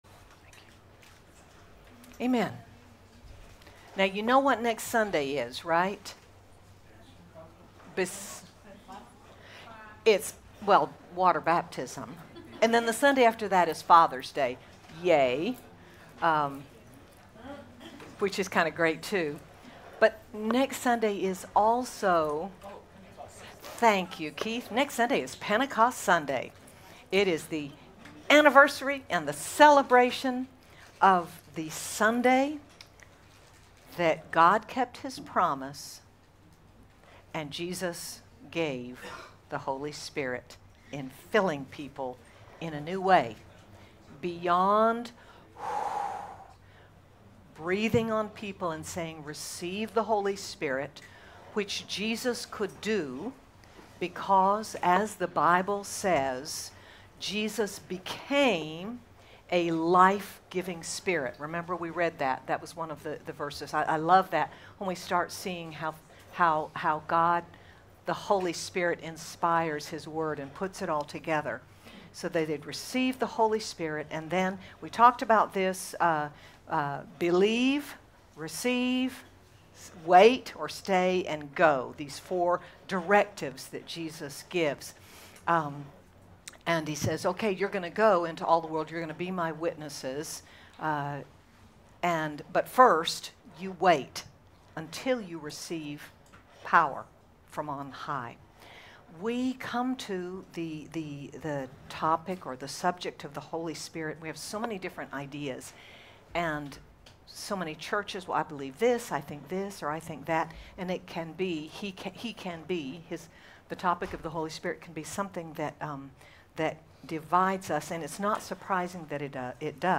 As we consider Jesus and how he ministered, we see how the Holy Spirit continues the work and life of Jesus in us today. Sermon by